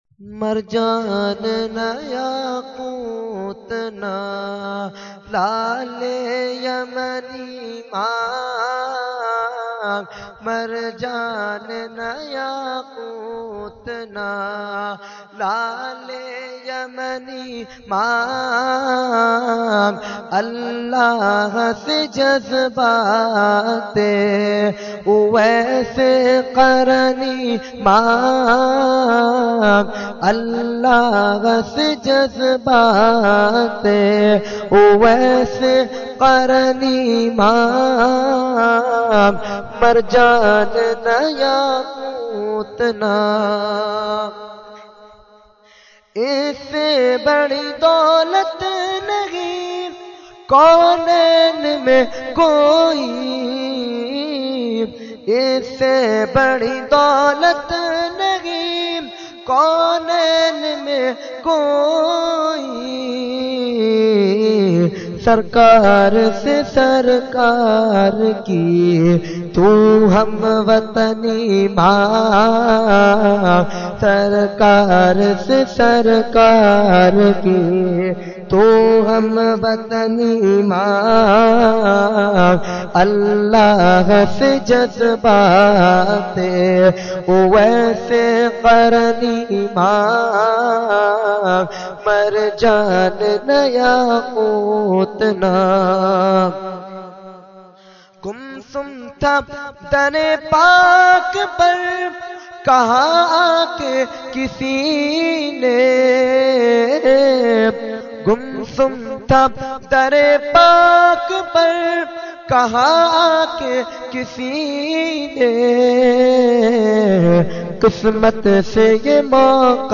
Category : Naat | Language : UrduEvent : Khatmul Quran 2014